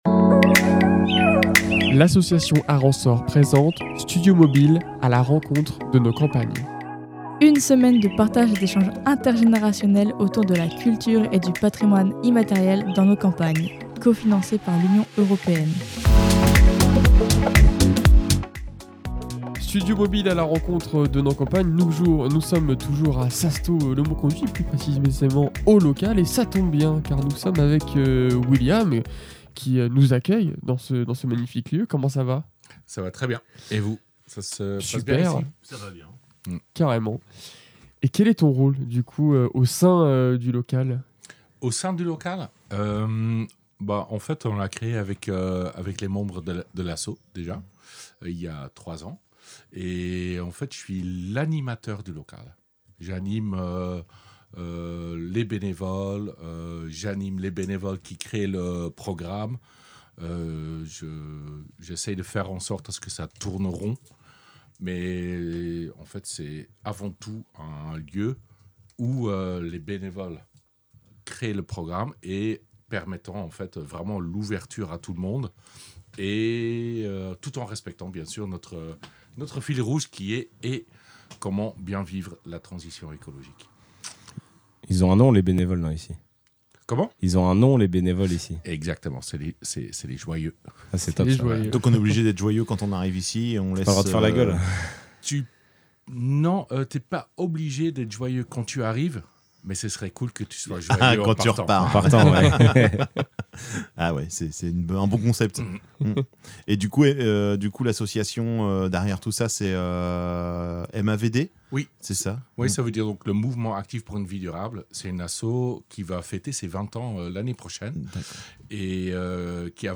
Studio mobile "A la rencontre de nos campagnes" caravane Studio mobile bonne humeur campagne joie sassetot-le-Mauconduit